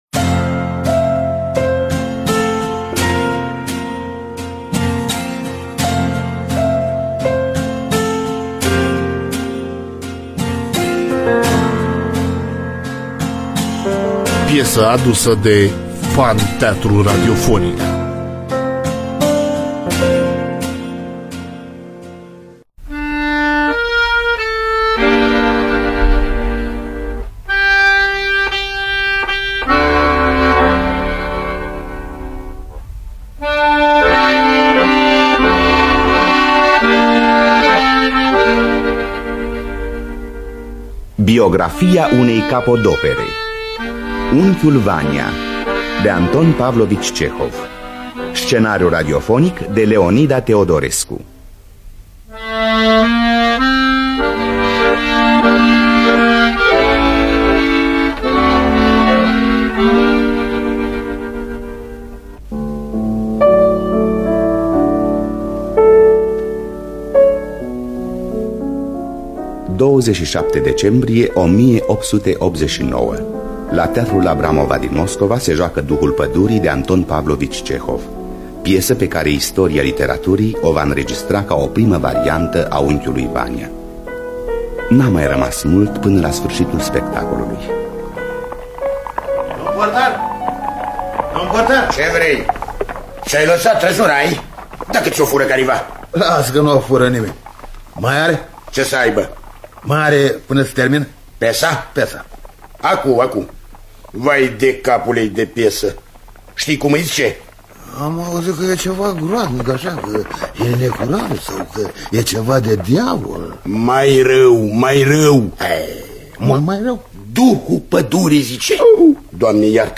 Biografii, Memorii: Anton Pavlovici Cehov – Unchiul Vanea (1973) – Teatru Radiofonic Online